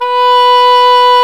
WND OBOE3 B4.wav